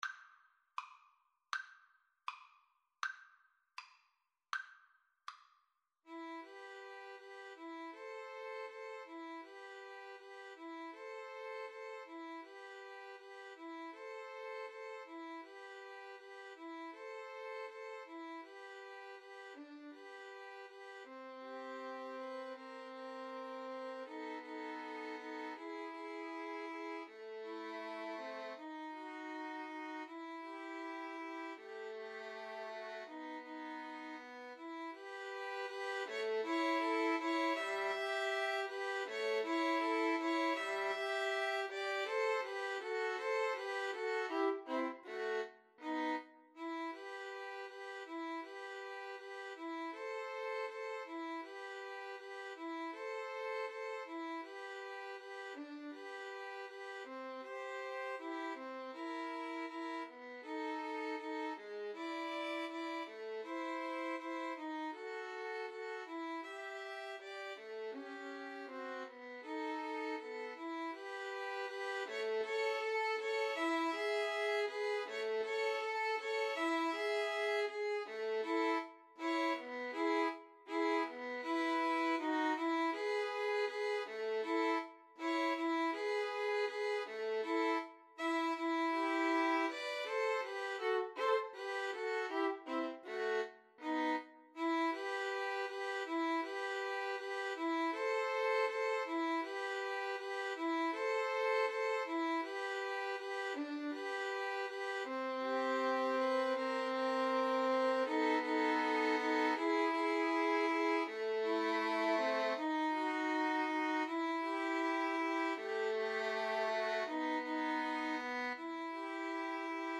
~ = 100 Andante
Violin Trio  (View more Intermediate Violin Trio Music)
Classical (View more Classical Violin Trio Music)